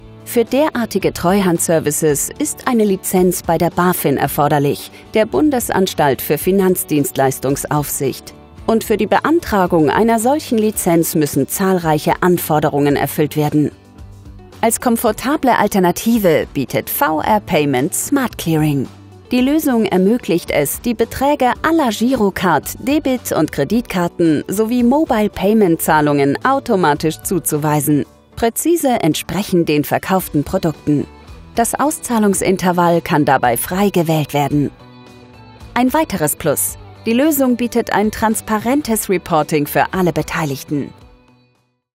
dunkel, sonor, souverän, sehr variabel
Mittel minus (25-45)
Narrative, Tutorial